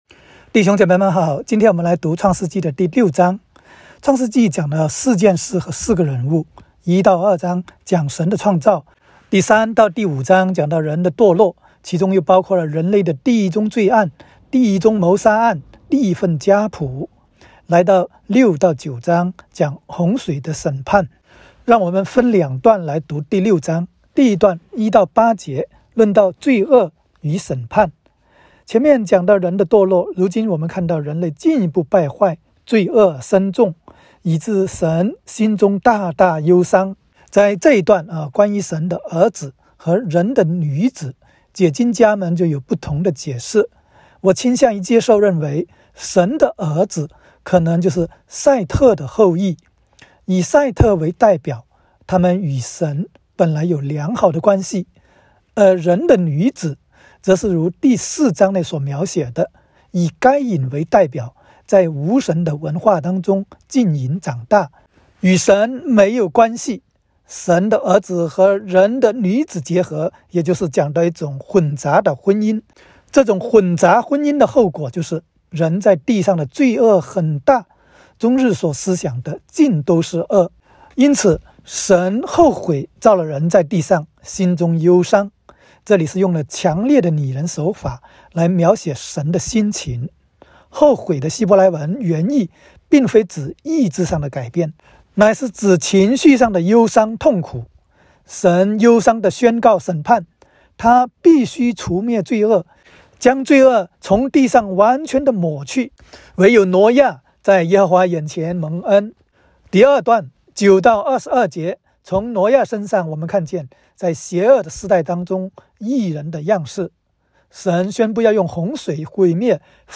创06（讲解-国）.m4a